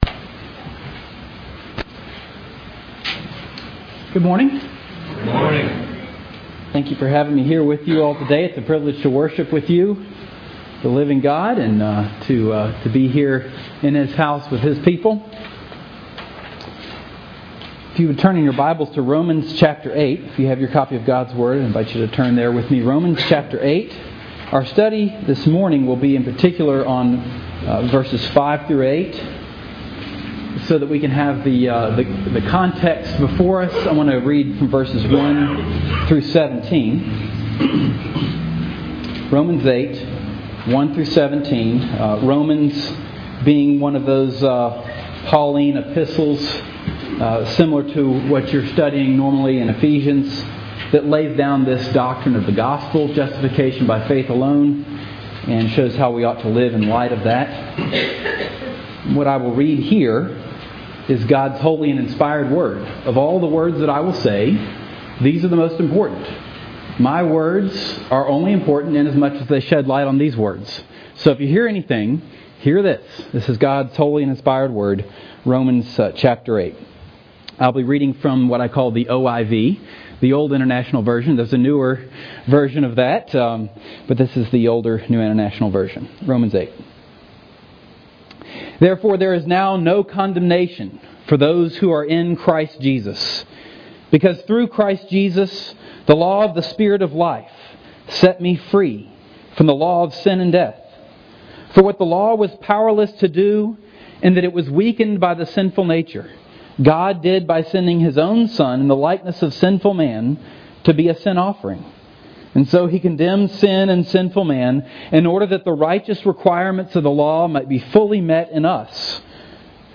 Service Type: Special non-Sunday service